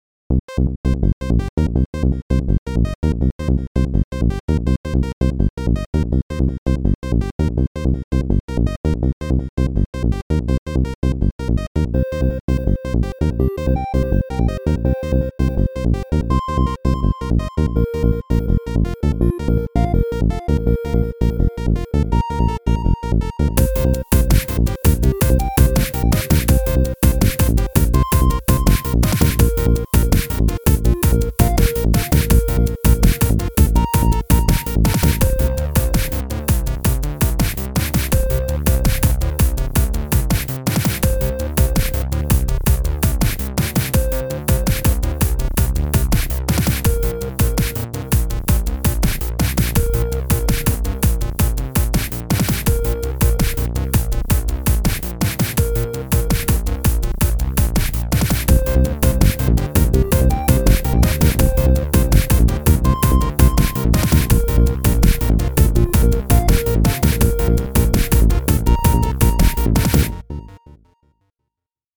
This one's a bit faster, but it takes forever to go nowhere.